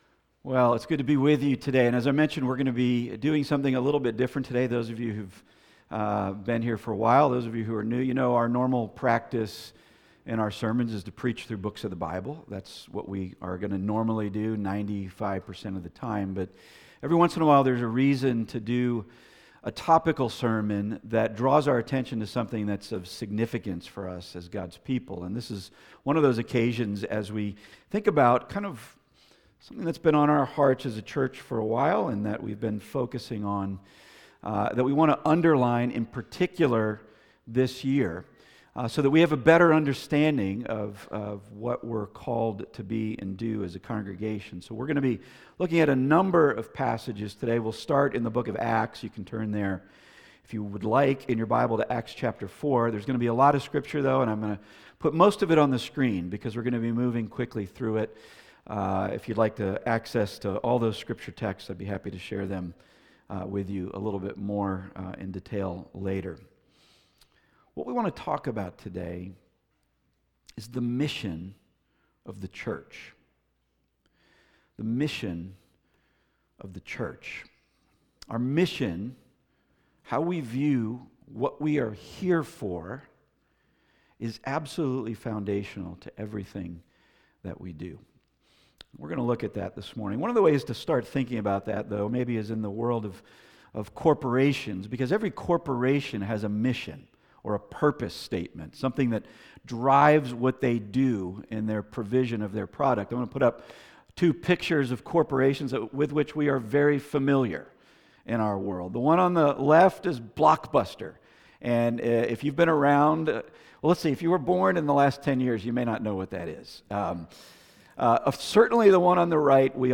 Passage: Acts 4:8-12 Service Type: Weekly Sunday